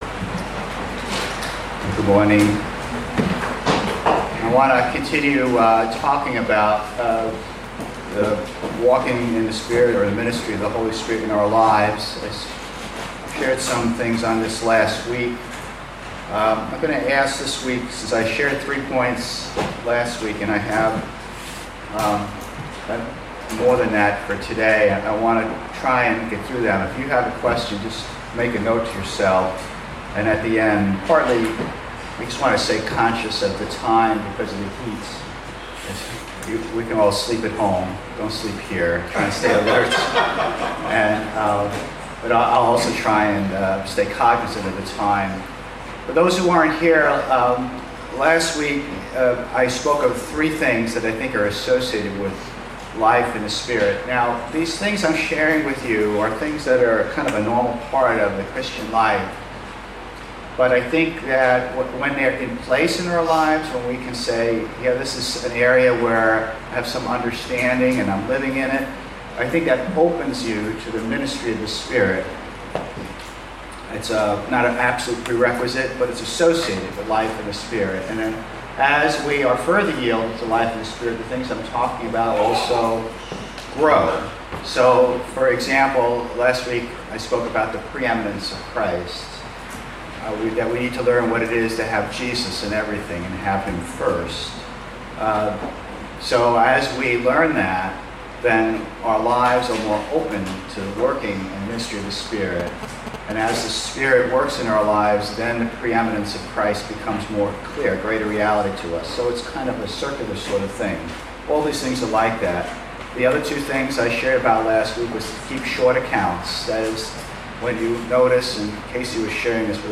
He explains that these points are associated with a normal Christian life and that as we yield to the Spirit, our understanding and practice of these areas will grow. The sermon covers several lessons